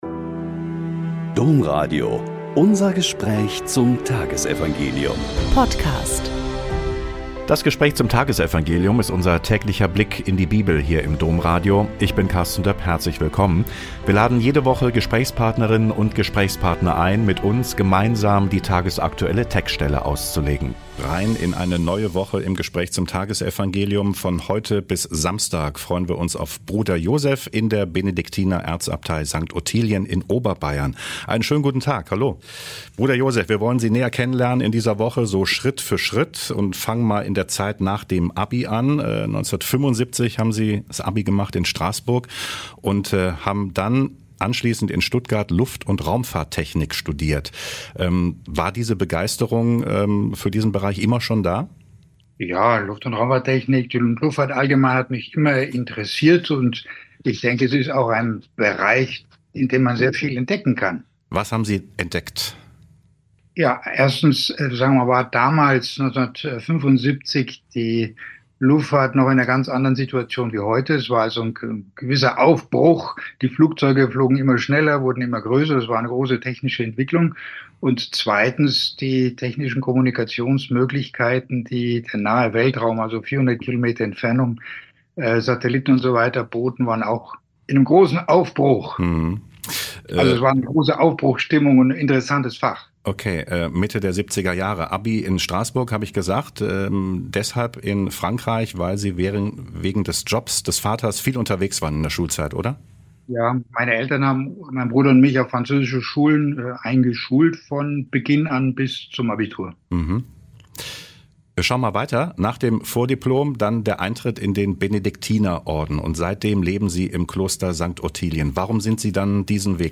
Lk 6,6-11 - Gespräch